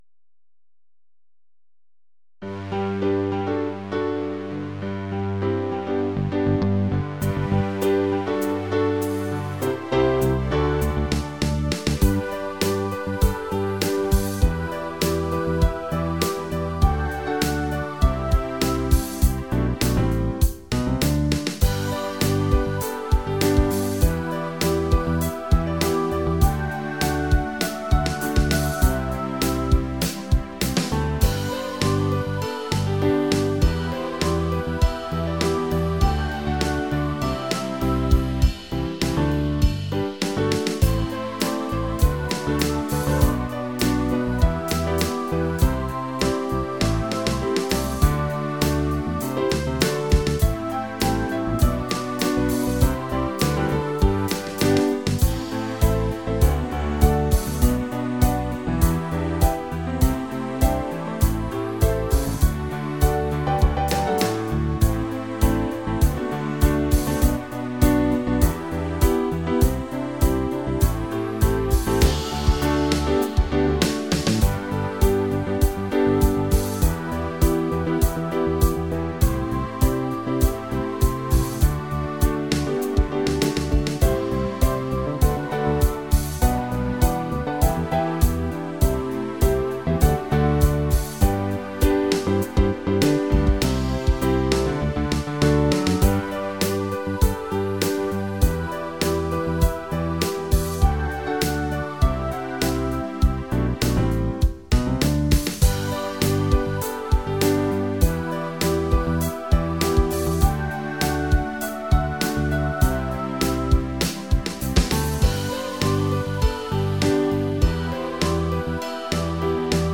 podklad MP3
hymnav-rap-b.mp3